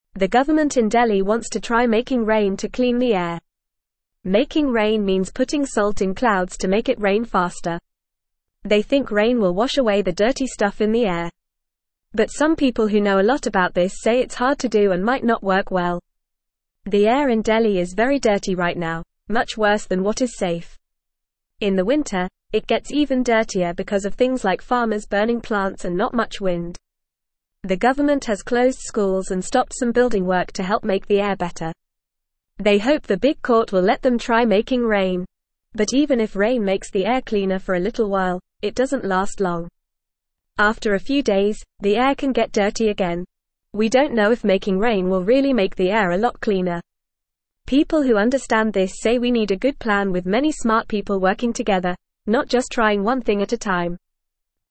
Fast
English-Newsroom-Lower-Intermediate-FAST-Reading-Making-Rain-to-Clean-Delhis-Dirty-Air.mp3